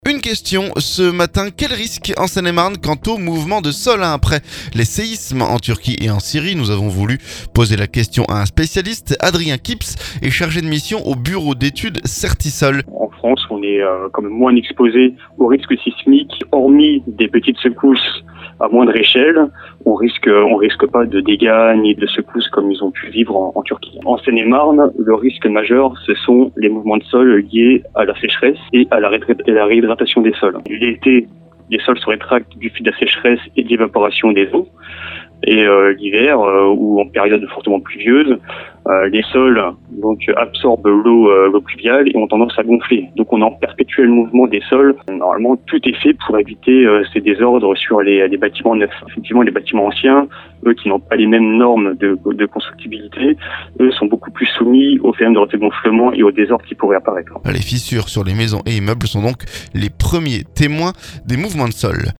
Une question ce lundi : quels risques en Seine-et-Marne quant au mouvement de sols ? Après les séismes en Turquie et en Syrie, nous avons voulu poser la question à un spécialiste.